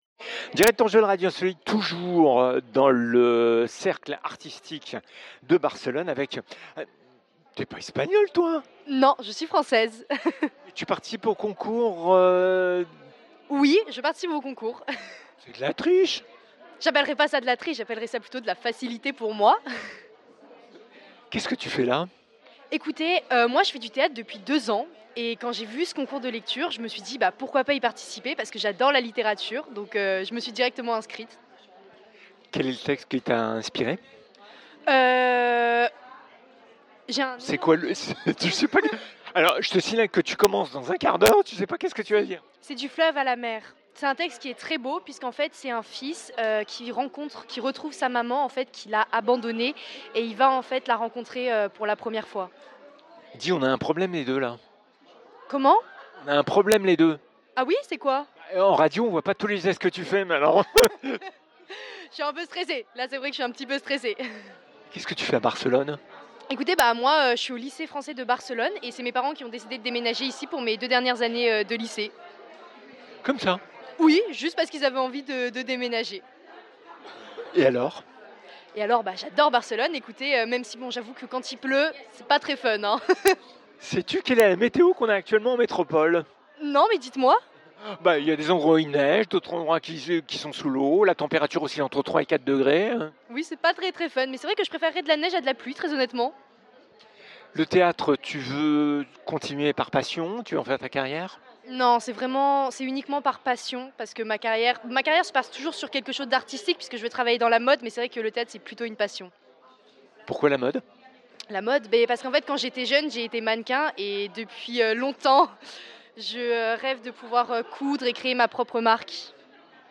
4 candidats du concours de lecture du Oui festival de Barcelone
Mettre en voix un court extrait de texte de théâtre d’autrices et d’auteurs contemporains.
Se glisser, seule ou à deux, dans la peau de la langue française comme si c’était un déguisement !